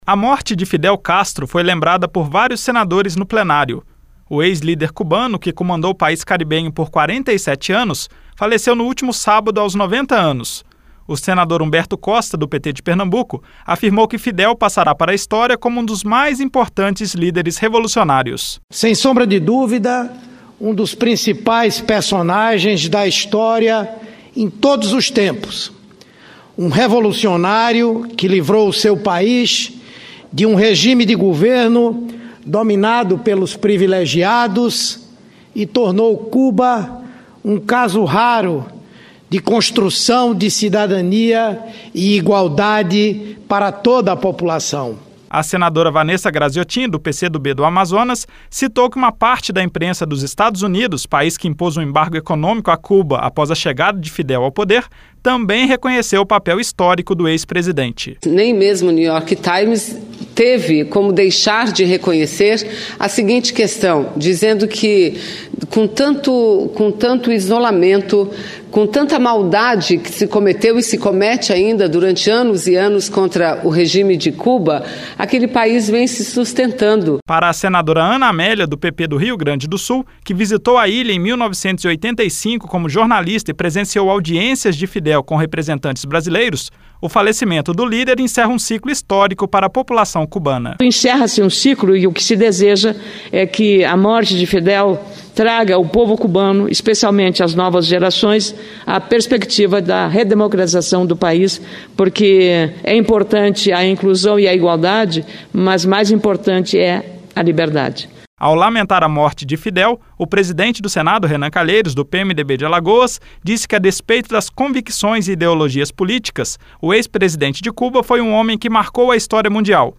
Senadores comentaram em Plenário o falecimento do ex-presidente cubano Fidel Castro. Fidel foi um dos líderes da revolução que em 1959 depôs o governo de Fulgêncio Batista e instalou o socialismo na ilha caribenha.